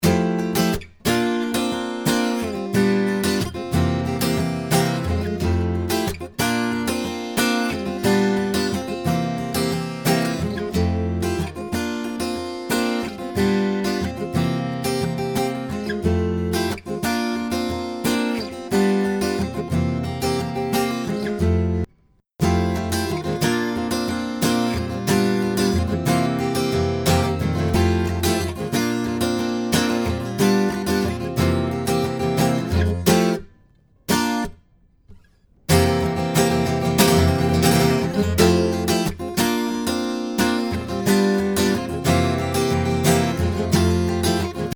Gear wise i'm using an apogee duet/Macbook Pro/PT10 and this acoustic guitar track was recorded in a shed (dry wall on 3 walls) with some blankets hung around the mic area. I recorded with a Rode NT1A near the 12th fret and a 57 on the bridge.
Acoustic Guitar Test Tracks.mp3